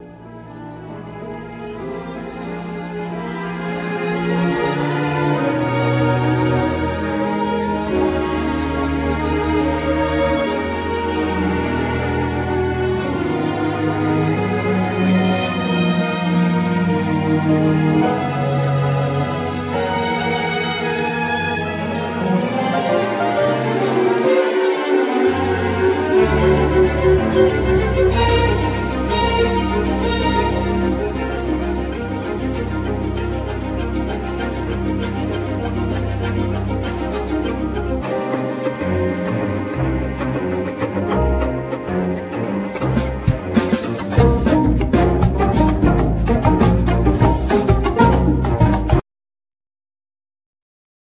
Acoustic Violin,Electric Violin,Tenor Violin
Piano,French horn
Double Bass
Drums
Percussion
Violin,Viola,Cello,Arco Bass